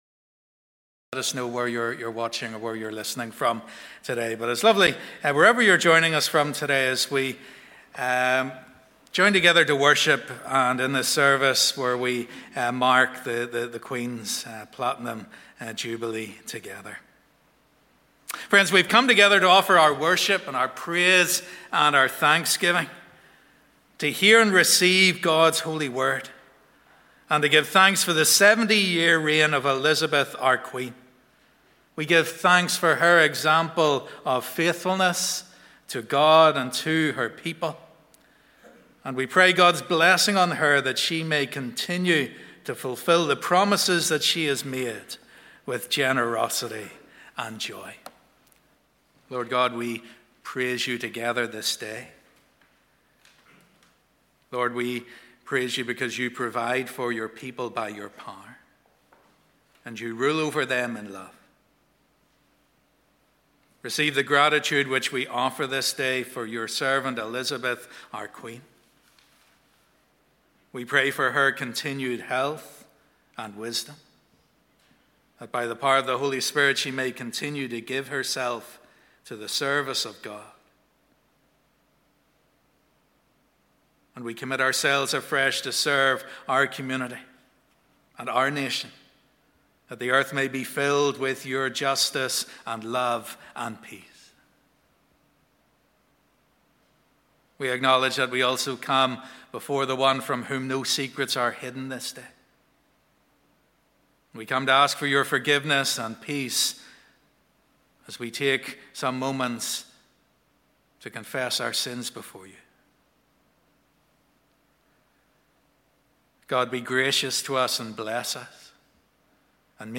Queen's Platinum Jubilee Thanksgiving Service
Live @ 10:30am Morning Service